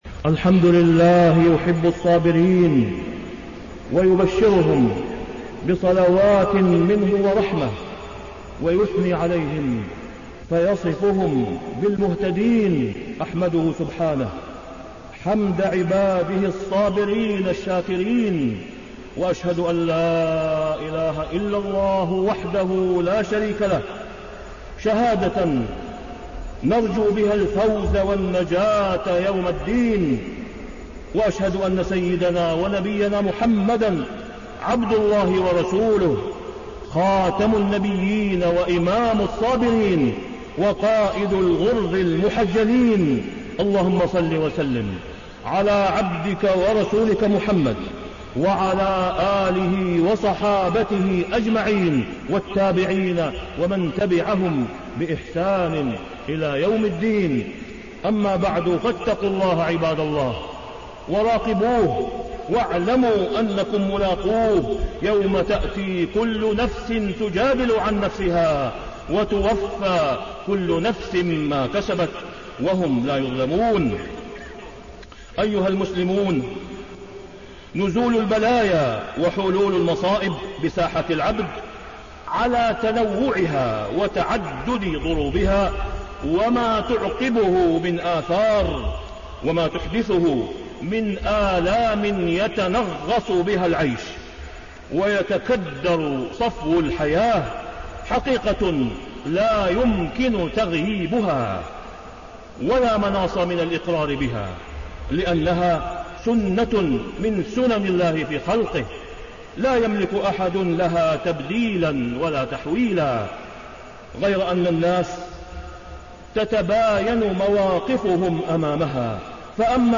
تاريخ النشر ١ ذو الحجة ١٤٣٢ هـ المكان: المسجد الحرام الشيخ: فضيلة الشيخ د. أسامة بن عبدالله خياط فضيلة الشيخ د. أسامة بن عبدالله خياط الصبر وثمراته